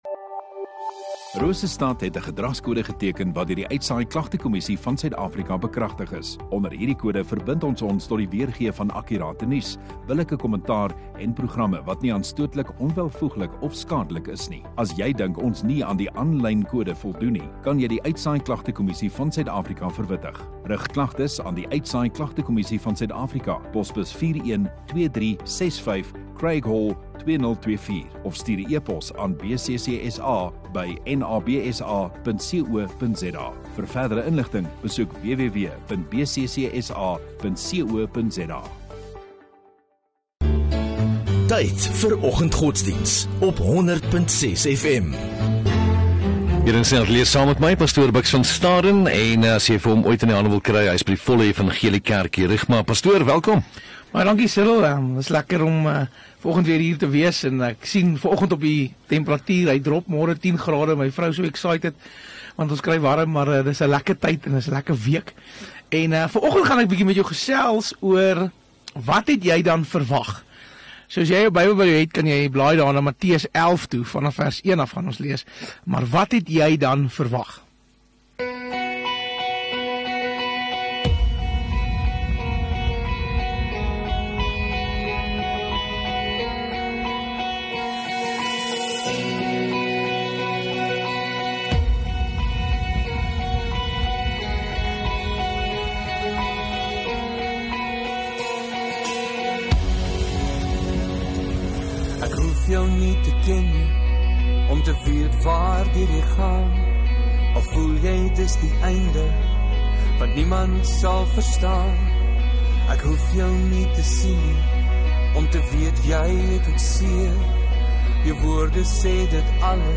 11 Oct Woensdag Oggenddiens